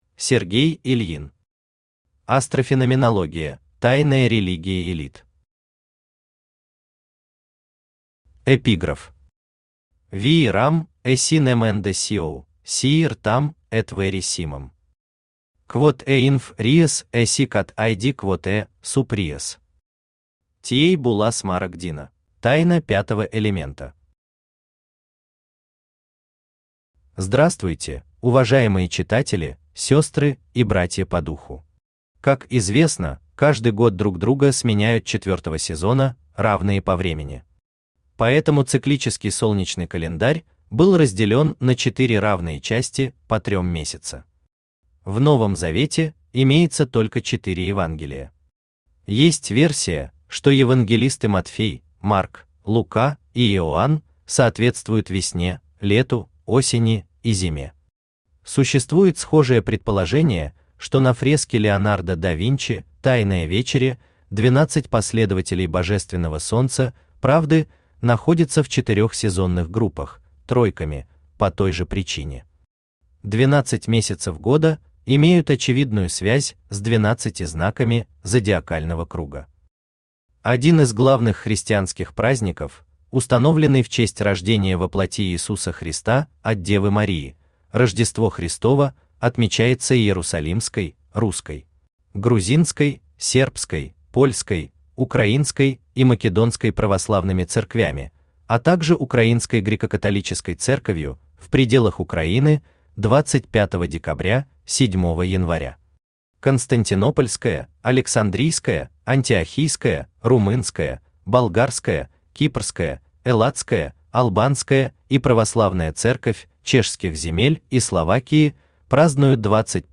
Аудиокнига Астрофеноменология: тайная религия элит | Библиотека аудиокниг
Aудиокнига Астрофеноменология: тайная религия элит Автор Сергей Ильин Читает аудиокнигу Авточтец ЛитРес.